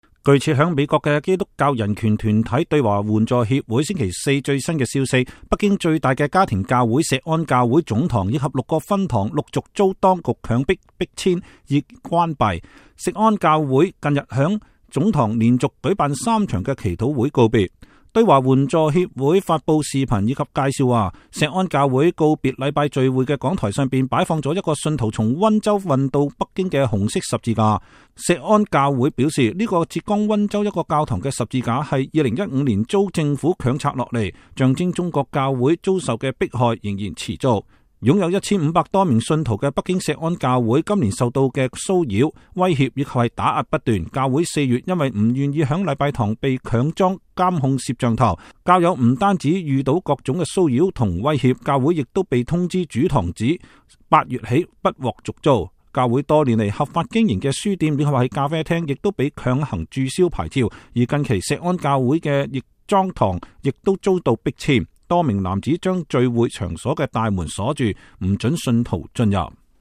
北京錫安教會告別祈禱聚會